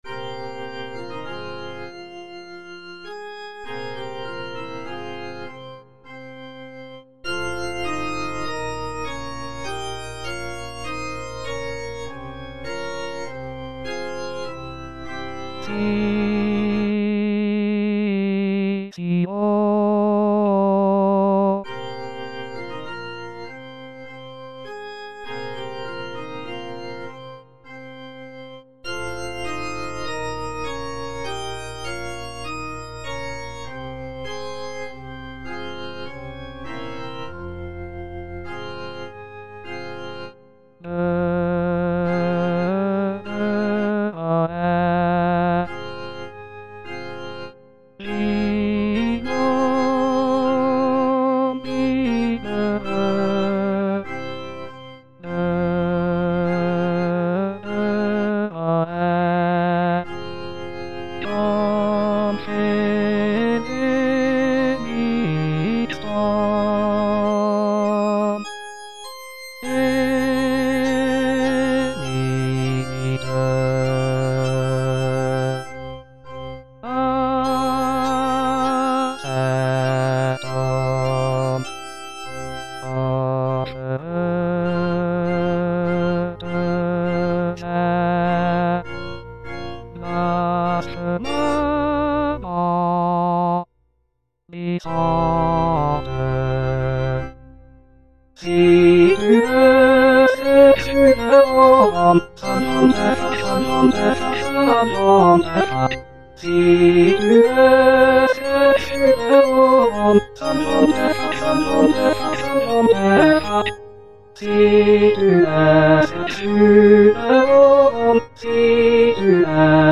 Parole 5: Sitio        Prononciation gallicane (à la française)